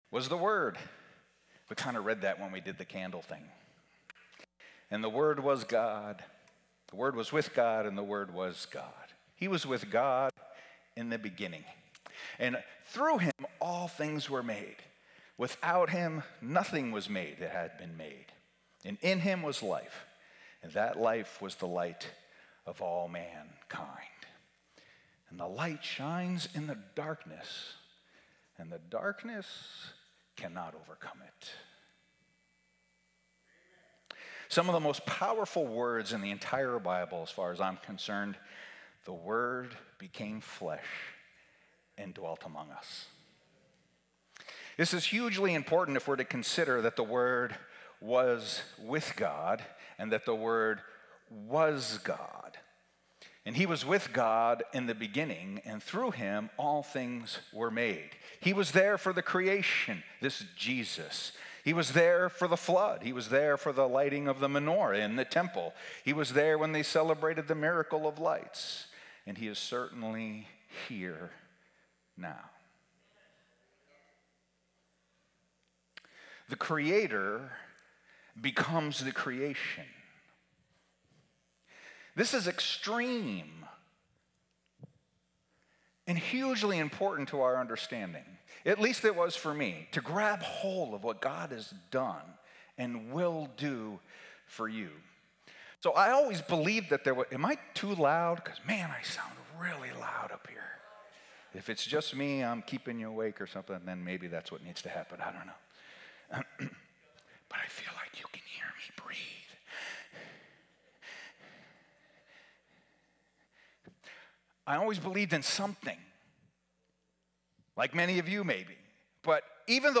Sermons | New Life Alliance Church